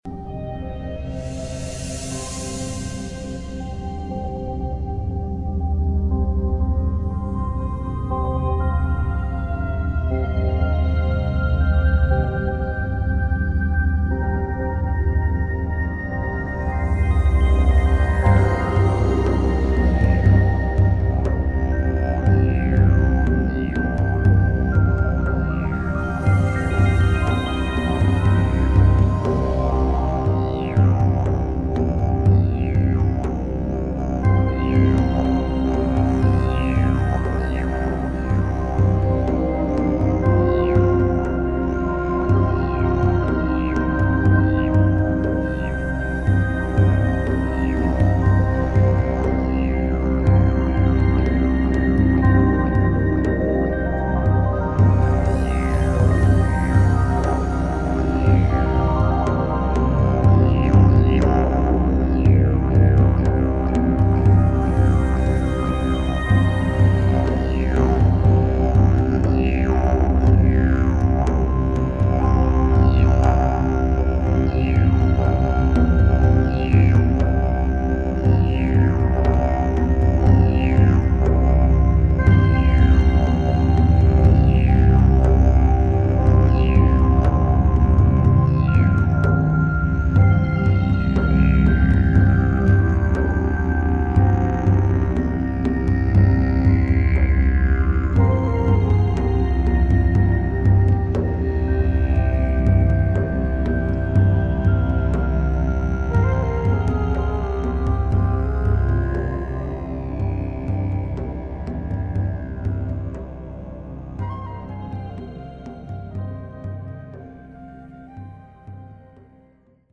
didgeridoo music